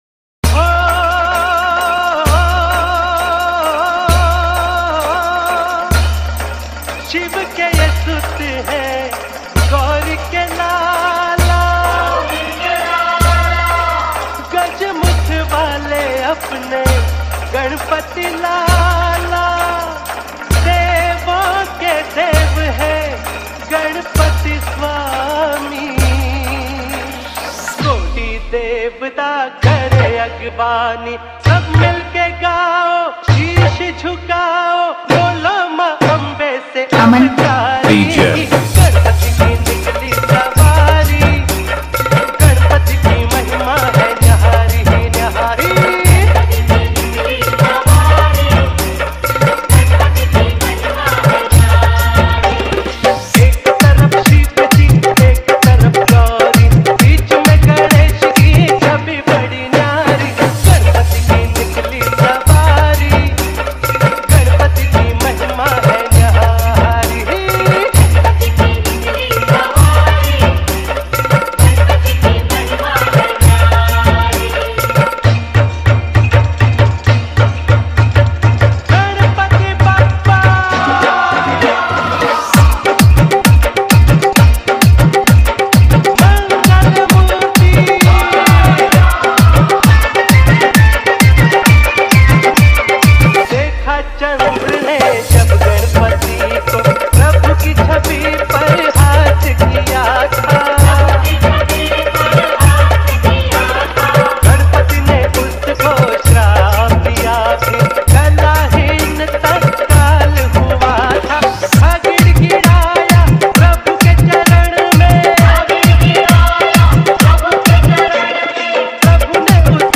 Ganesh Chaturthi Dj Remix Song
High Bass • Vibration Effect